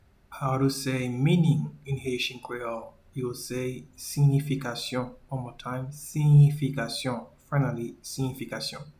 Pronunciation:
Meaning-in-Haitian-Creole-Siyifikasyon.mp3